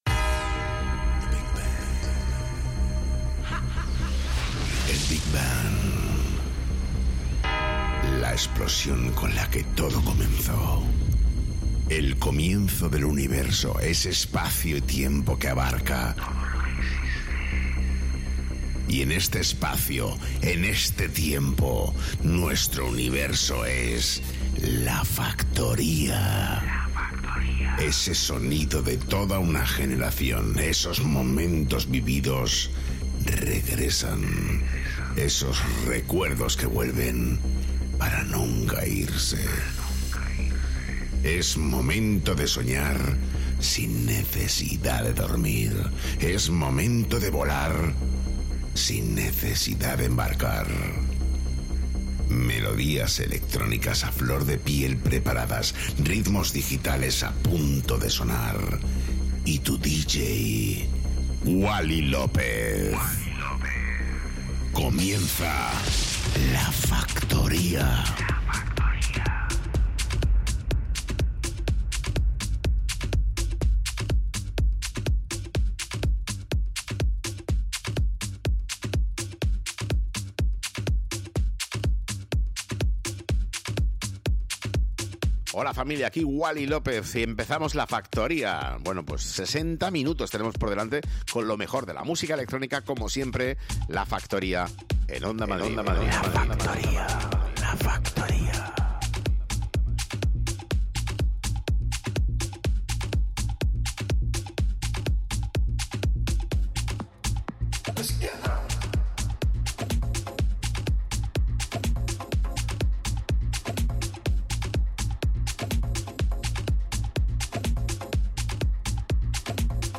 Wally López, el DJ más internacional de Madrid retoma La Factoría para todos los madrileños a través de Onda Madrid.